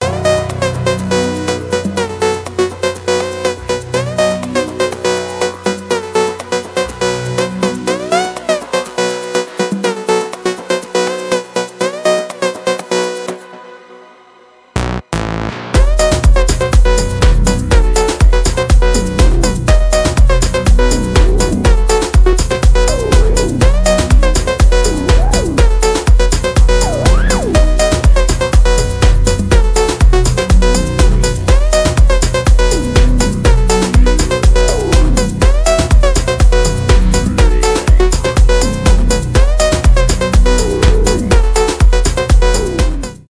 ритмичные
без слов
afro house , electronic